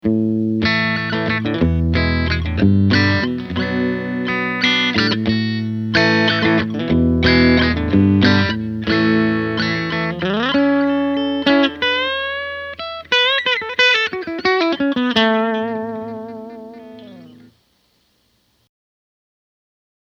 In any case, here are six versions of the same phrase with each different configuration: